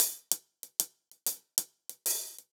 Index of /musicradar/ultimate-hihat-samples/95bpm
UHH_AcoustiHatA_95-04.wav